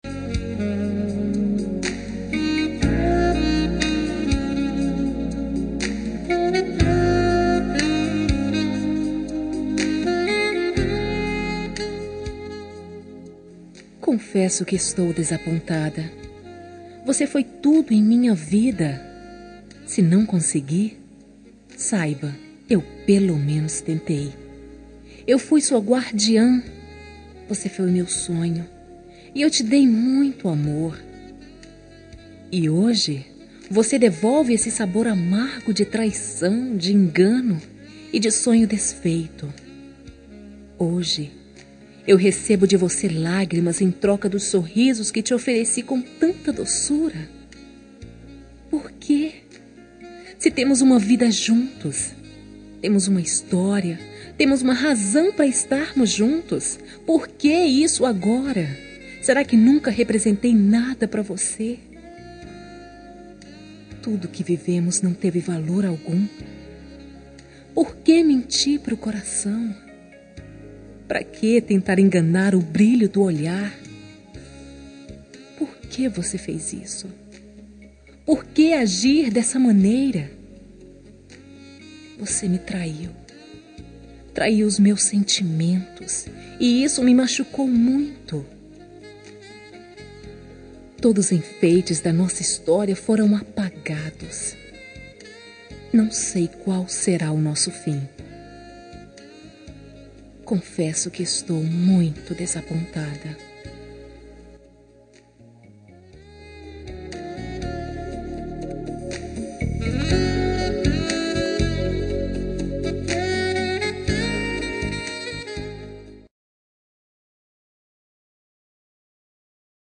Toque para Não Terminar – Voz Masculina – Cód: 469 – Você me Traiu